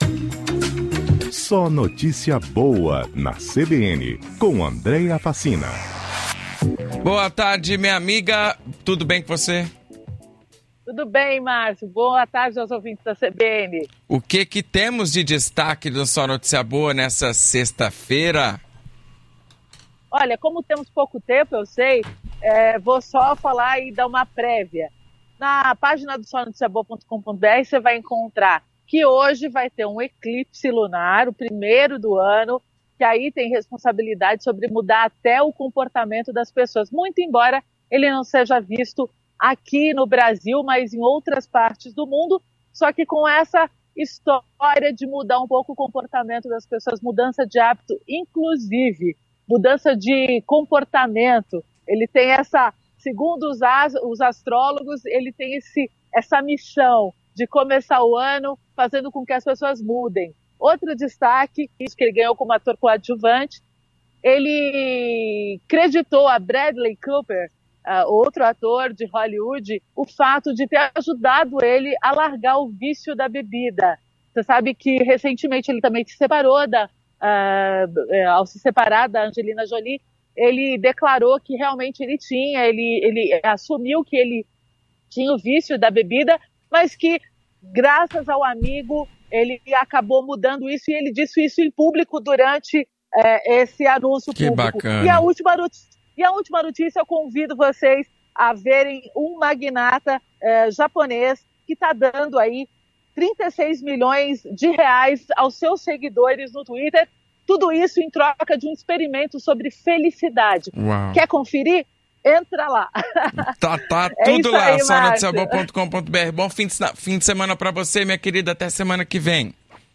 O quadro SNB na CBN vai ao ar de segunda a sexta às 16:55 na rádio CBN Grandes Lagos.